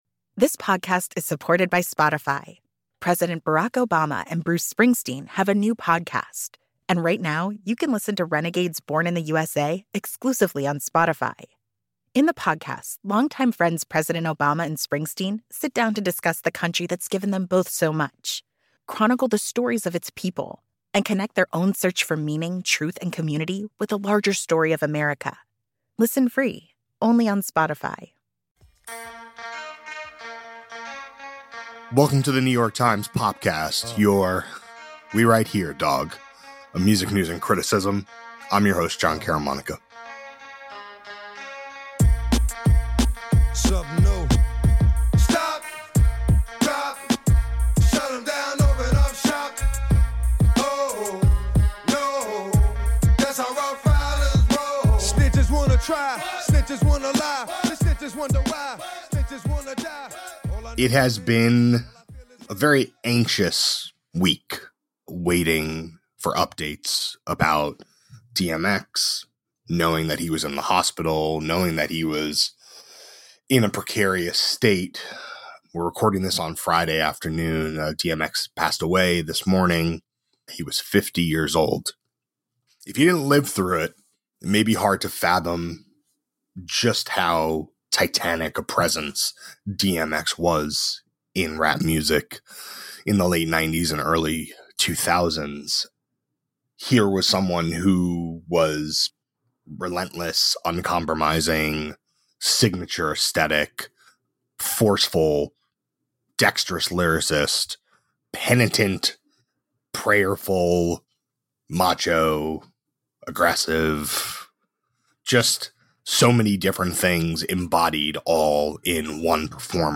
A conversation about the intense potency of the rapper’s music and religious fervor, and what it was like to interview him.